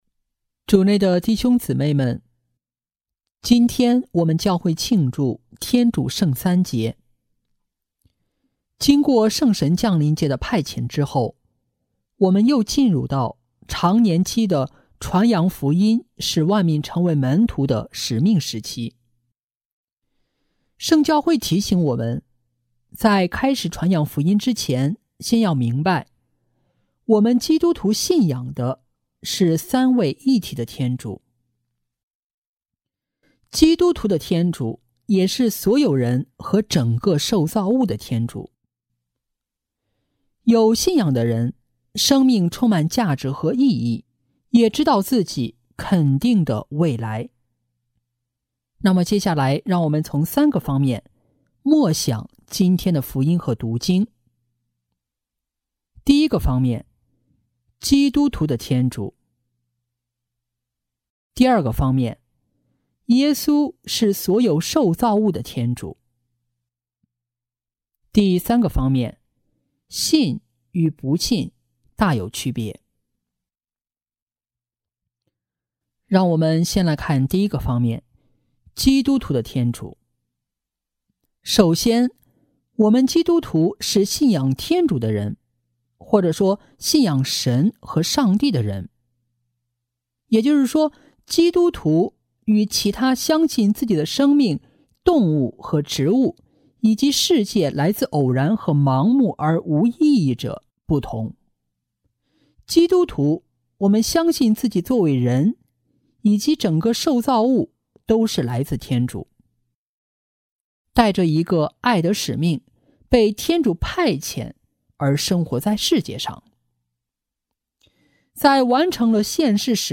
【主日证道】| 这就是我们的天主（甲-天主圣三节）